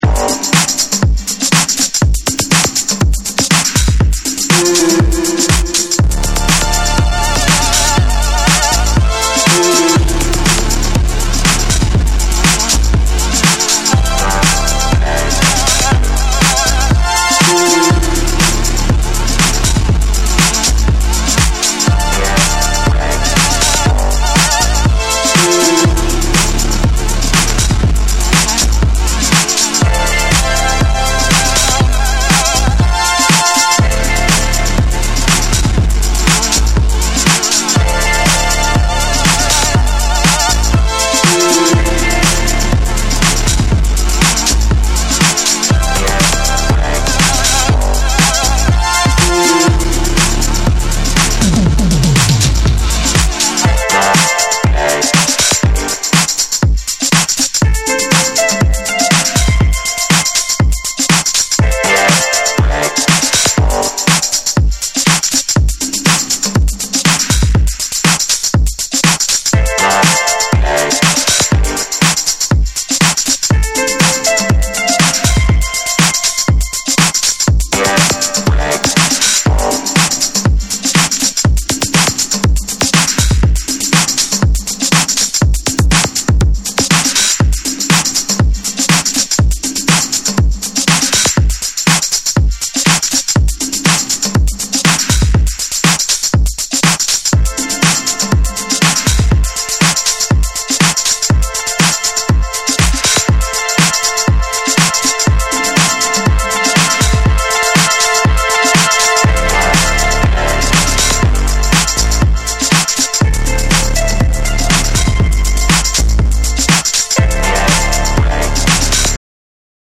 アーバンでソウルフルなシンセ・サウンドとブロークンなビートが交錯する、UKエレクトロニック・ソウルを収録。